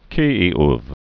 (kēē)